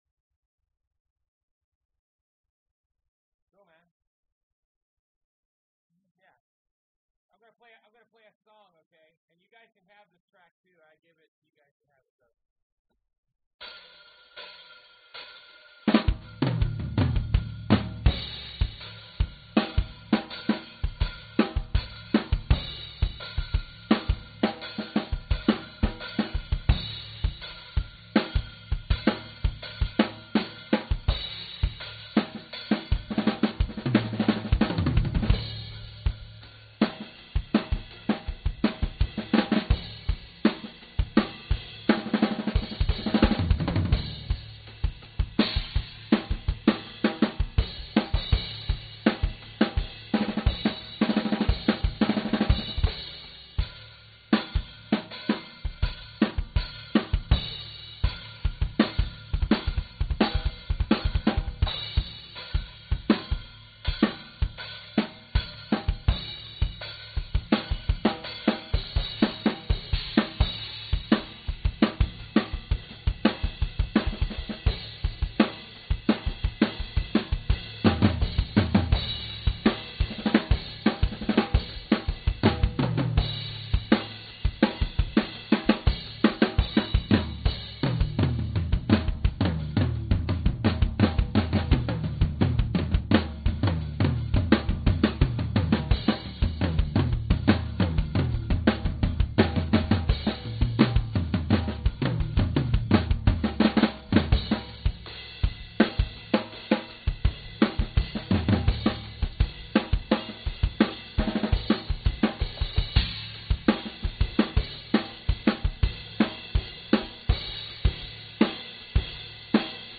描述：在圣地亚哥的艺术学院帮助录音班的自由式干扰。Awesome tones.